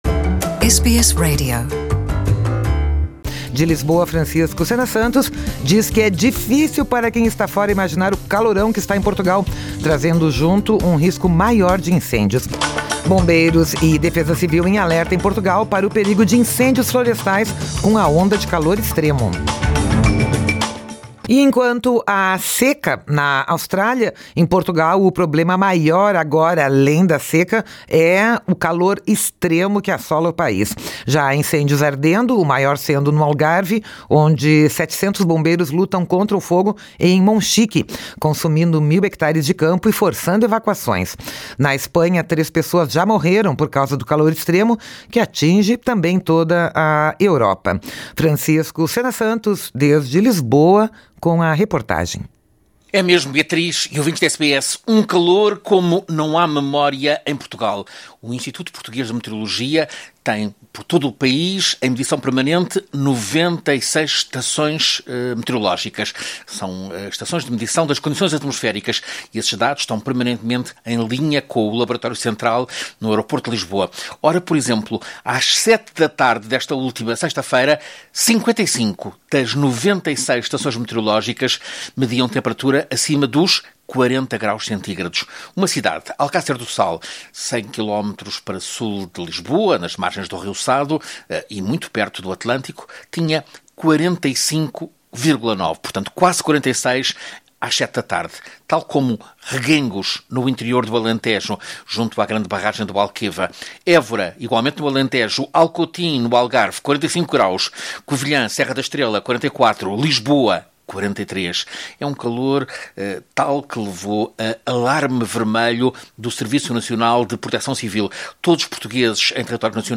desde Lisboa, com a reportagem.